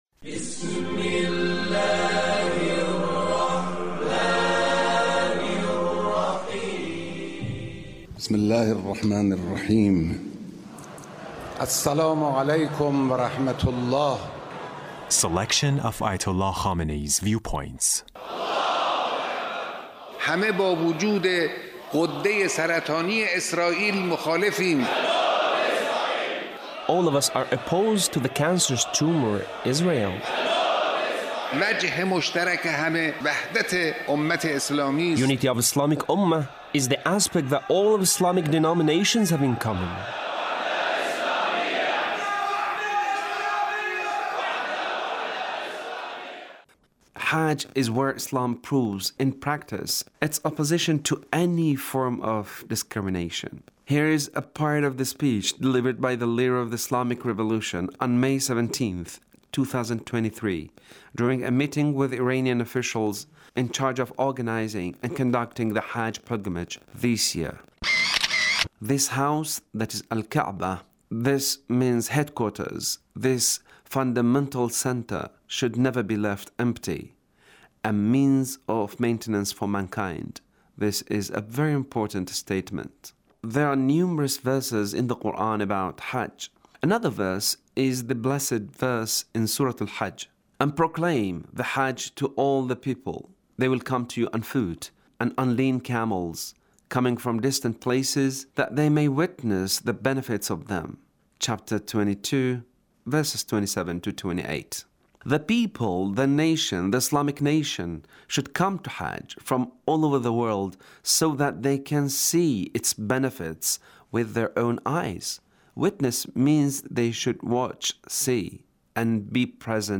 Leader's Speech in A Meeting with Iranian Commanders and Senior Military Officials